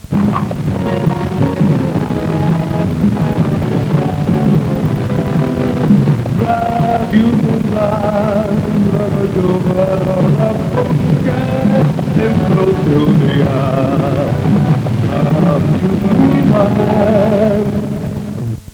Indicatiu de l'emissora
FM
Qualitat de l'àudio defectuosa.